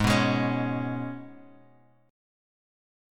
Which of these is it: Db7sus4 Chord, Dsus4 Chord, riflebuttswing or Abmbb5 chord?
Abmbb5 chord